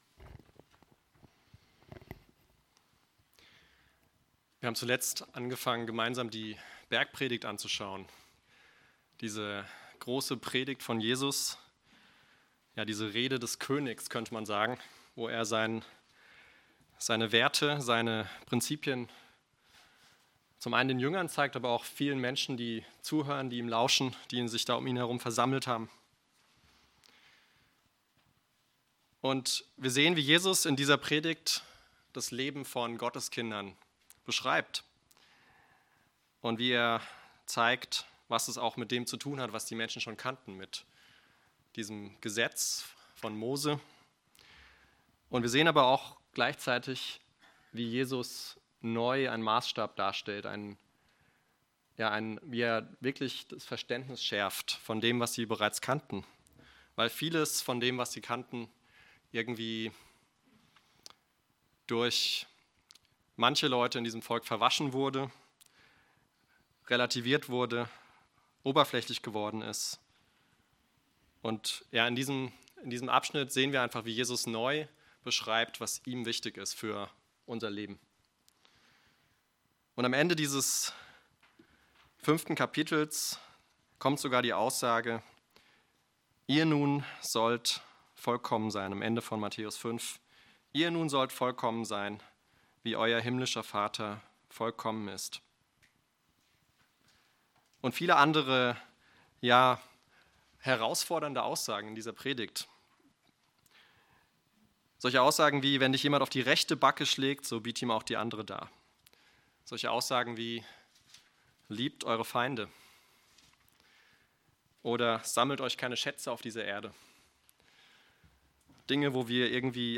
Aus der Predigtreihe: "Salz und Licht sein"